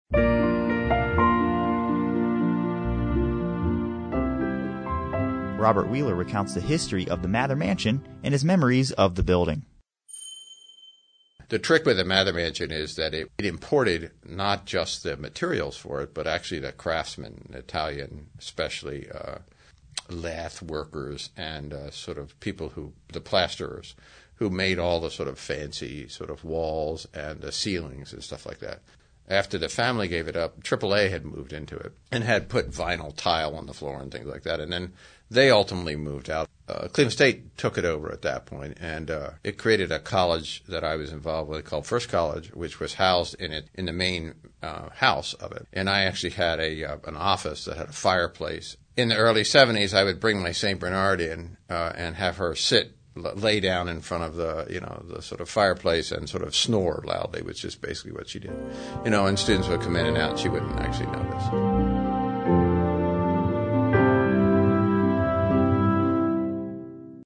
Source: Cleveland Regional Oral History Collection